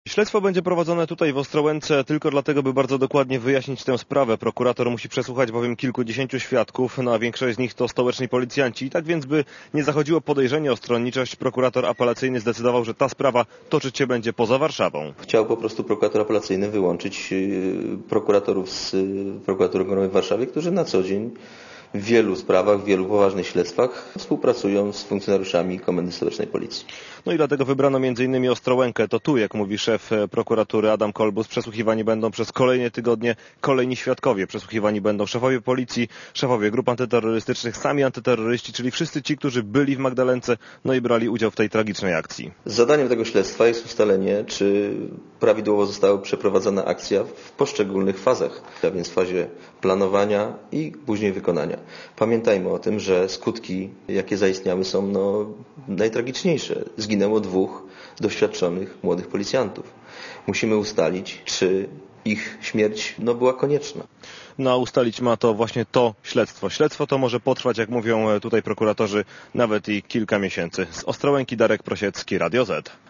Relacja reportera Radia Zet (285Kb)